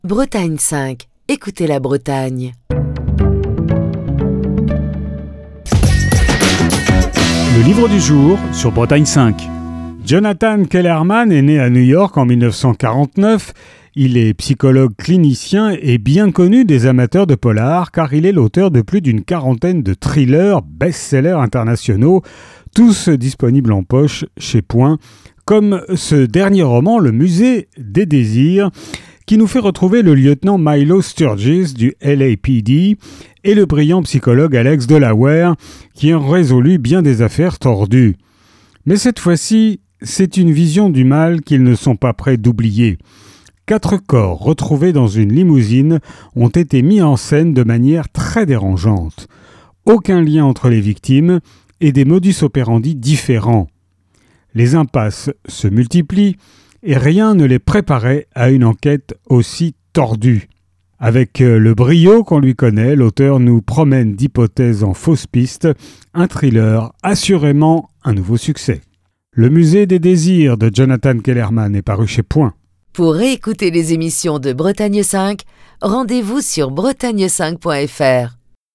Chronique du 20 novembre 2025.